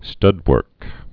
(stŭdwûrk)